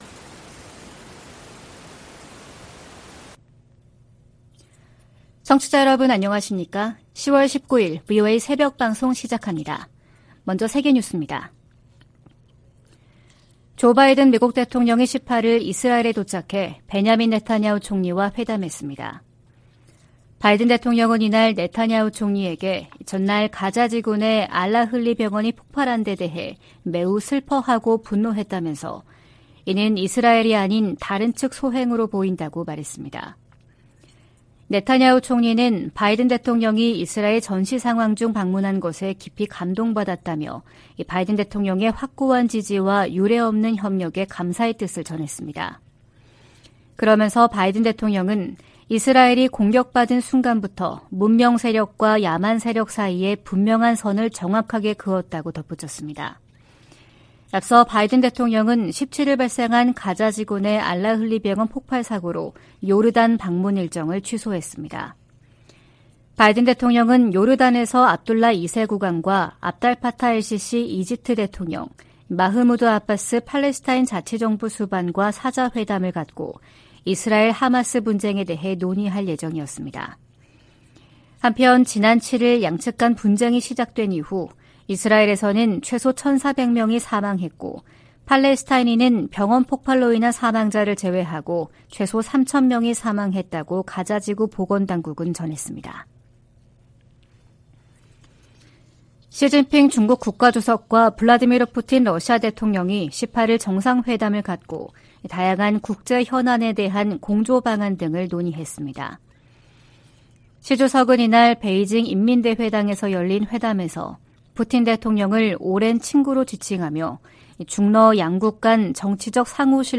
VOA 한국어 '출발 뉴스 쇼', 2023년 10월 19일 방송입니다. 미 국무부가 러시아 외무장관 방북과 관련해 러시아가 첨단 군사기술을 북한에 넘길 것을 우려하고 있다고 밝혔습니다. 미 인도태평양사령관은 북한과 러시아 간 무기 거래 등 최근 움직임으로 역내 위험성이 커졌다고 지적했습니다. 팔레스타인 무장 정파 하마스가 가자지구에서 북한제 무기를 사용한다고 주한 이스라엘 대사가 VOA 인터뷰에서 말했습니다.